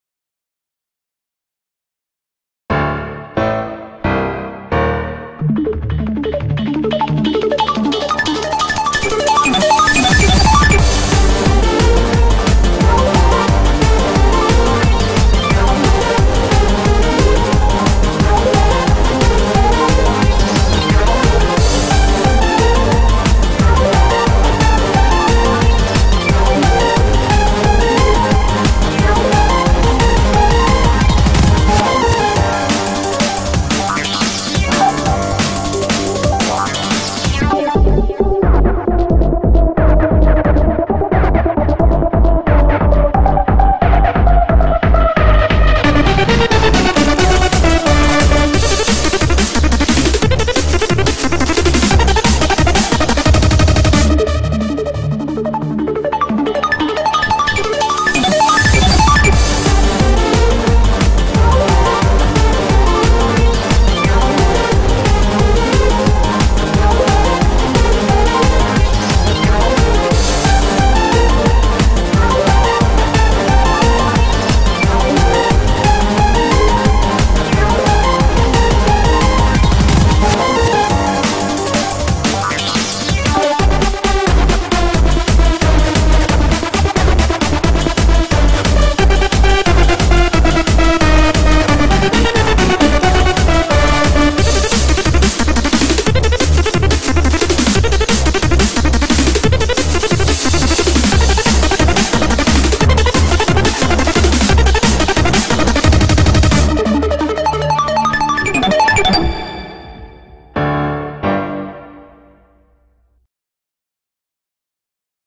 BPM89-178
Audio QualityCut From Video